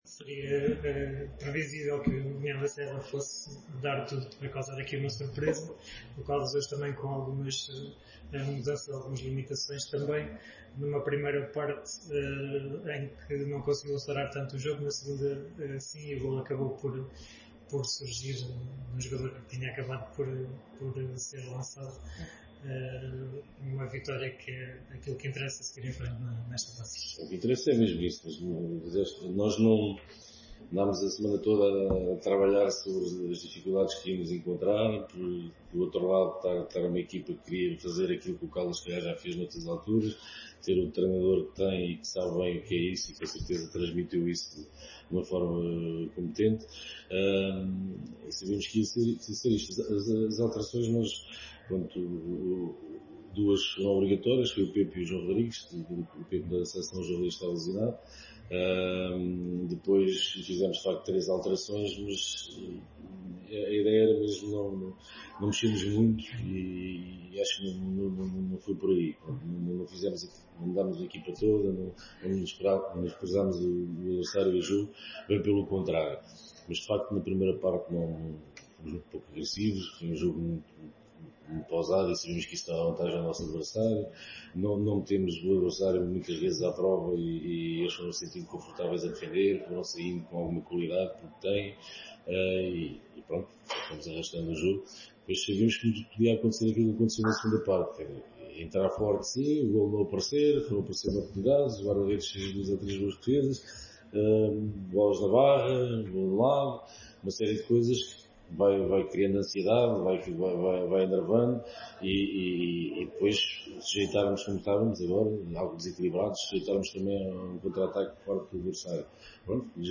no final do encontro